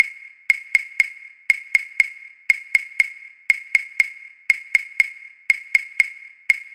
claves.mp3